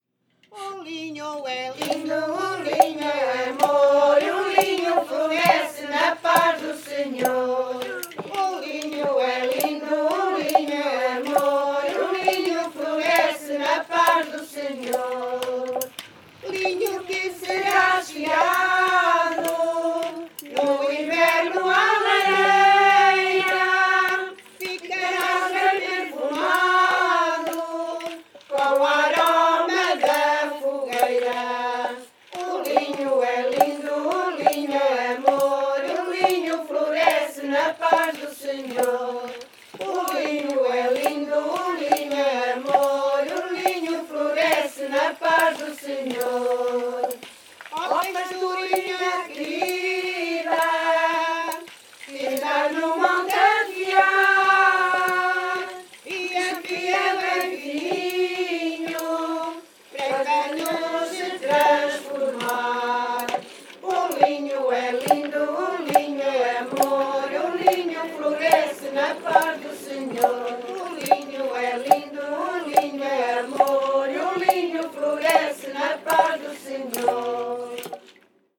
Grupo Etnográfico de Trajes e Cantares do Linho
O linho é lindo (Várzea de Calde, Viseu)